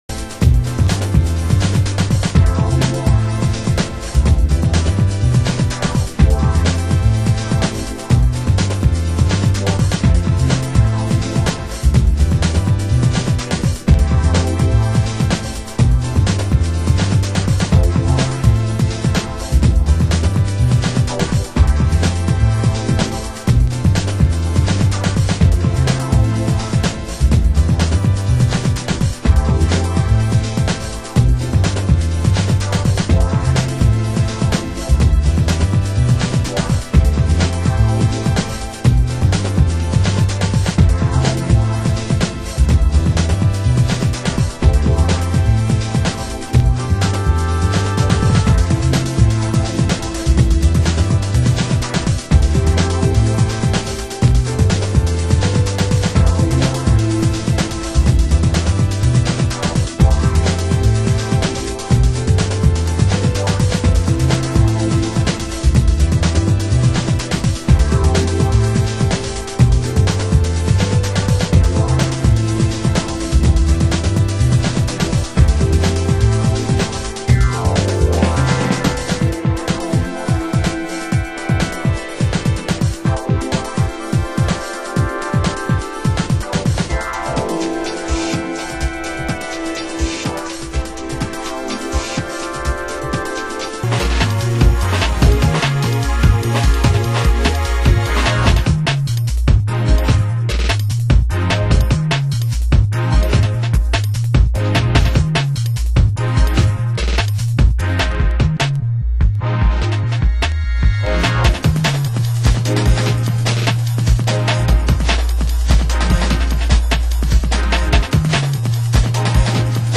Genre Down Tempo